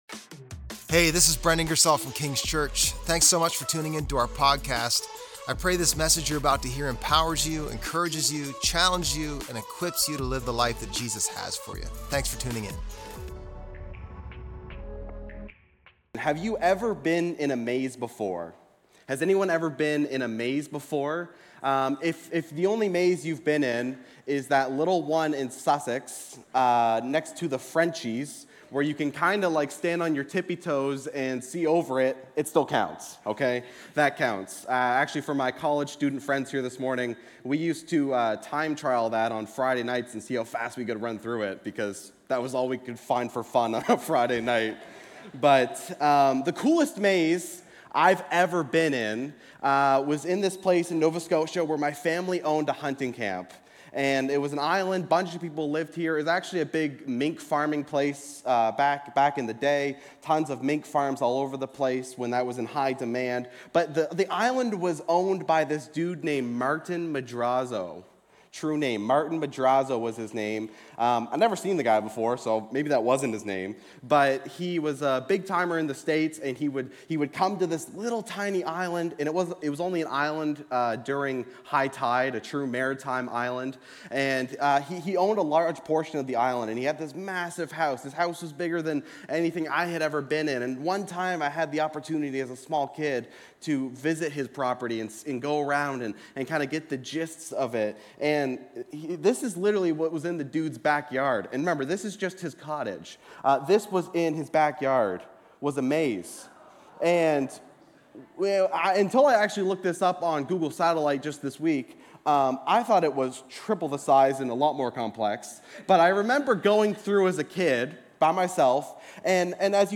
Mar_23_Sermon_Podcast.mp3